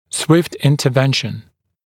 [swɪft ˌɪntə’venʃn][суифт ˌинтэ’веншн]быстрое вмешательство